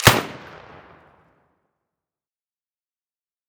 Sounds / Weapons